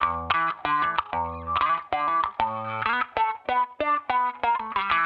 Index of /musicradar/sampled-funk-soul-samples/95bpm/Guitar
SSF_StratGuitarProc2_95E.wav